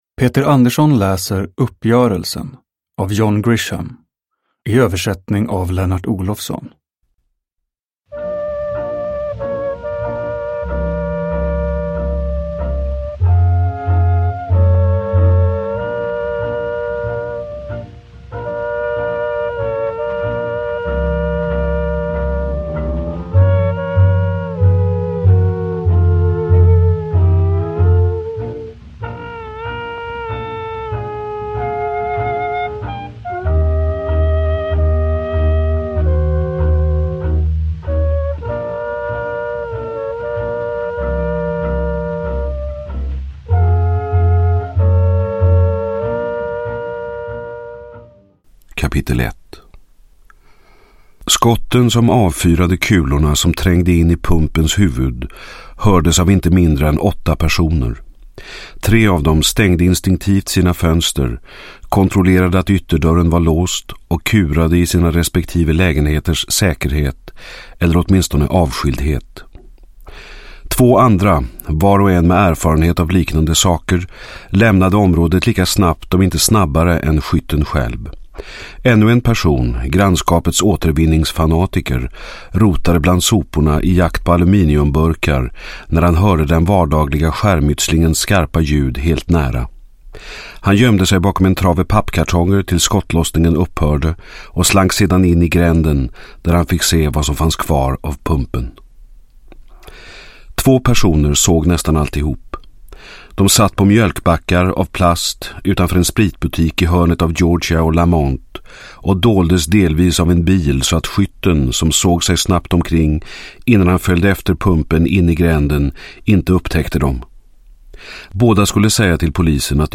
Uppgörelsen (ljudbok) av John Grisham